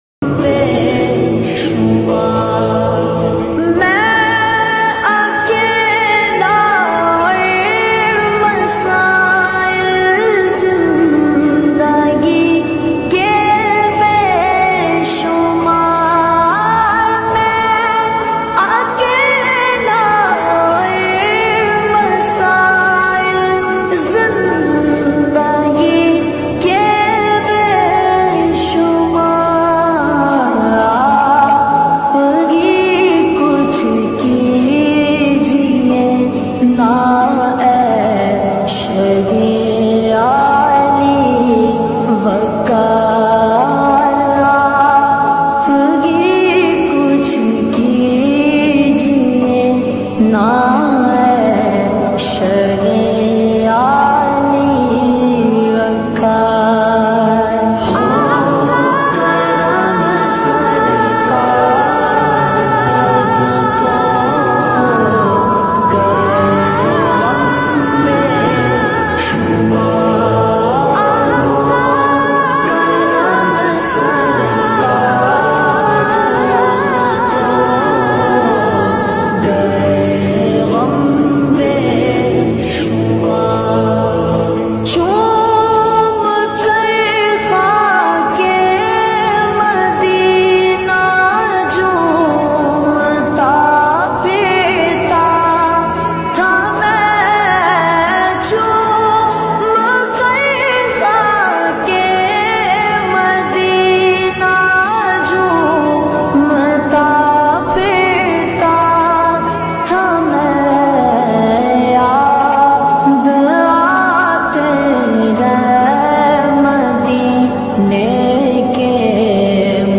Islamic Full Naat
Slowed & Reverb